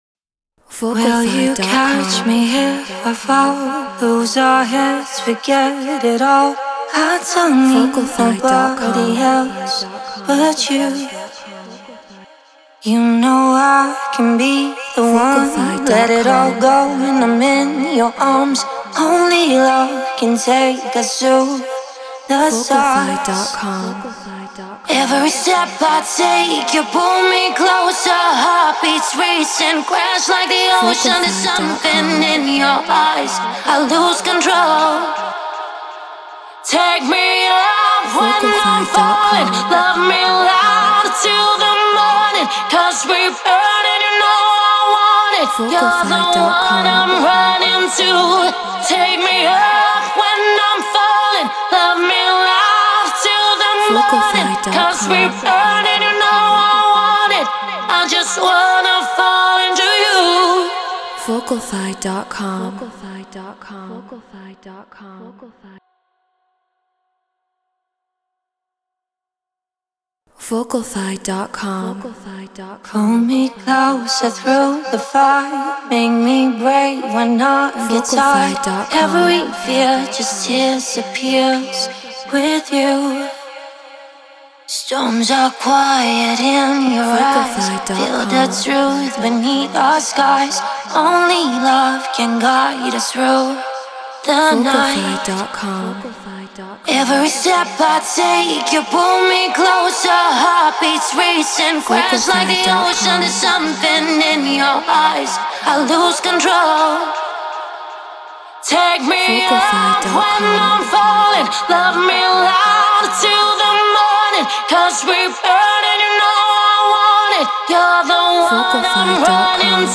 Hard Dance 160 BPM A#min
Brauner VMX Apogee Elements 88 Ableton Live Treated Room